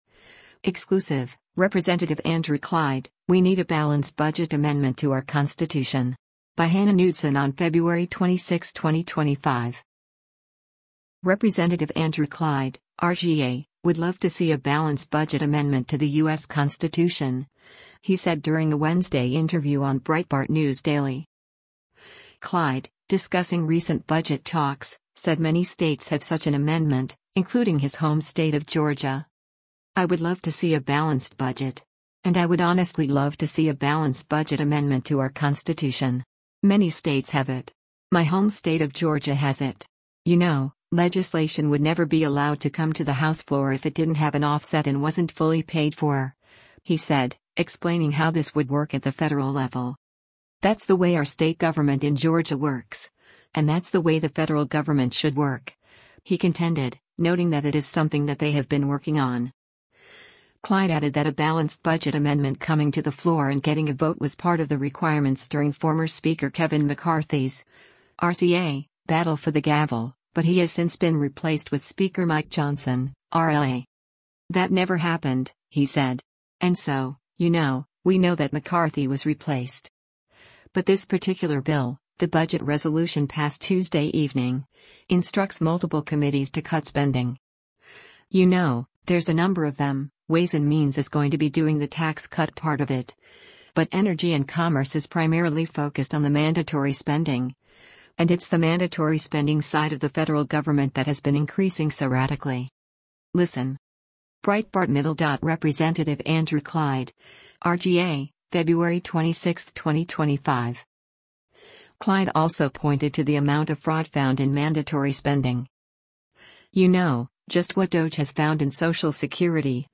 Rep. Andrew Clyde (R-GA) would love to see a balanced budget amendment to the U.S Constitution, he said during a Wednesday interview on Breitbart News Daily.